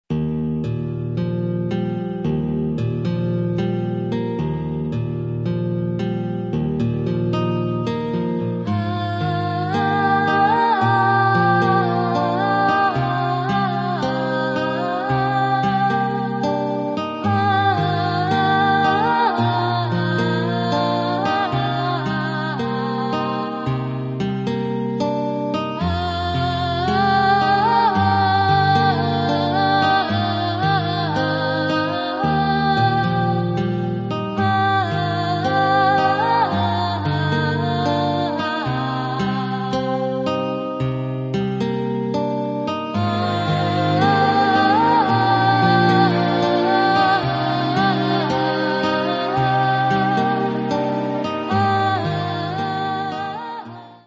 作曲・編曲・シンセサイザー演奏